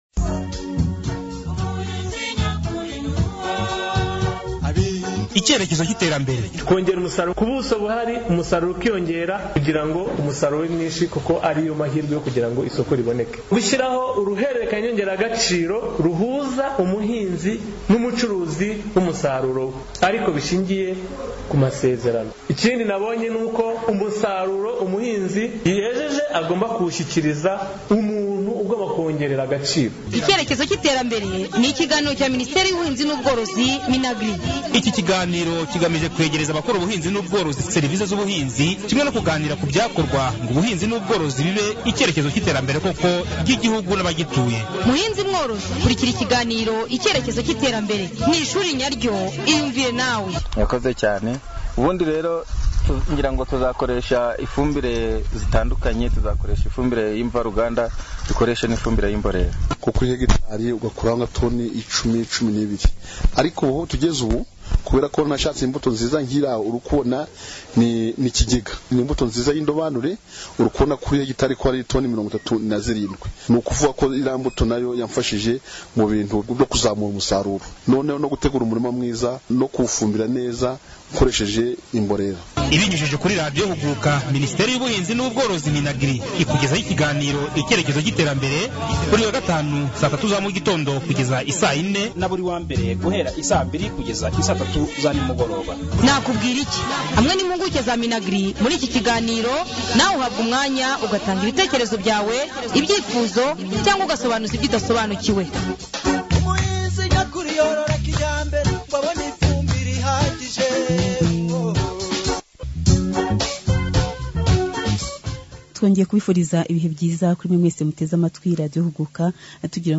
Ikiganiro ku ndwara z' Imyumbati